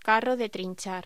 Locución: Carro de trinchar
voz